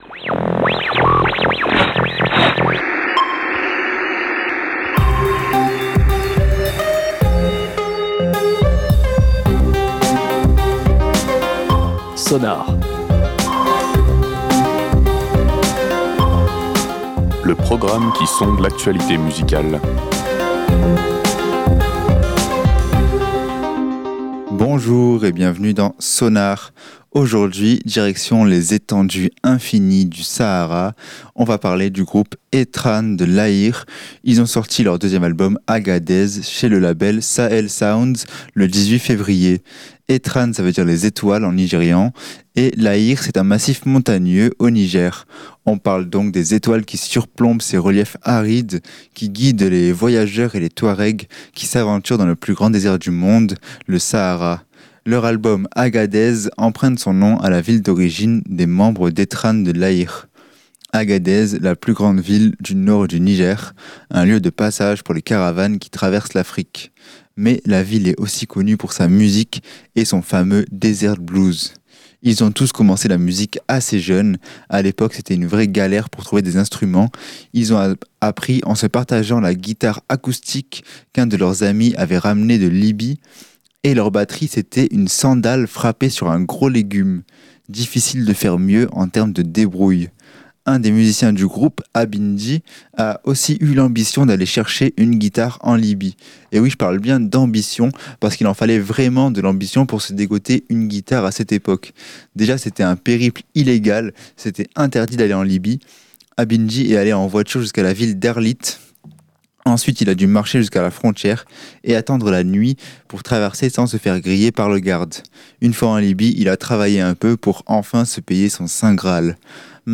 Réécoutez l'émission